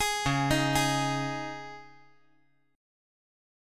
C#sus2 Chord
Listen to C#sus2 strummed